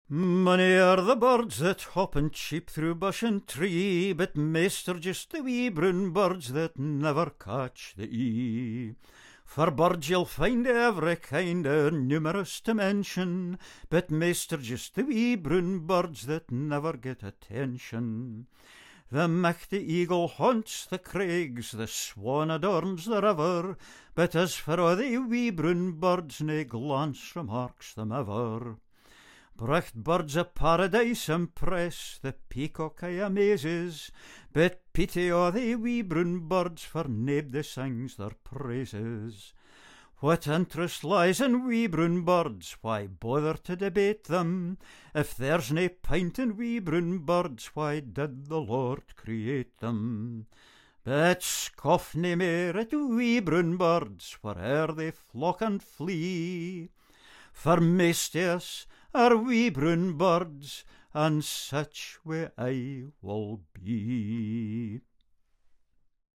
Scots Song